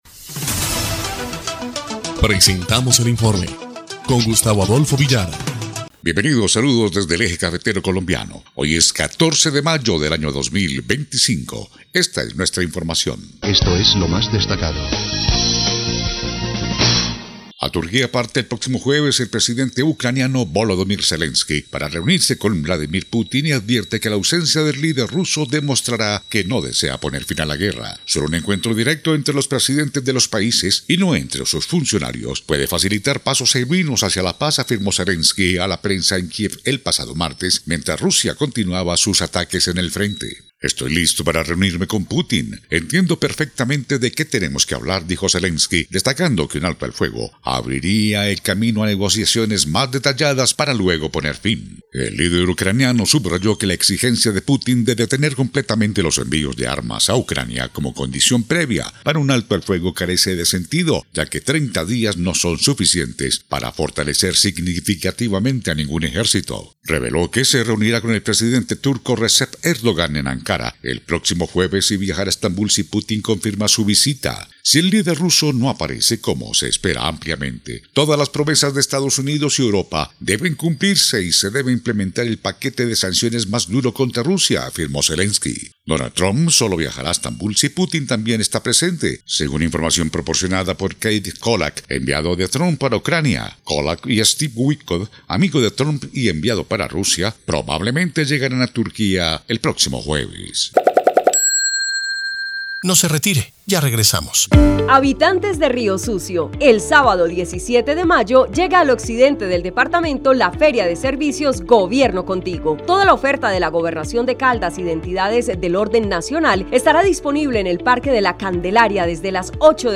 EL INFORME 1° Clip de Noticias del 14 de mayo de 2025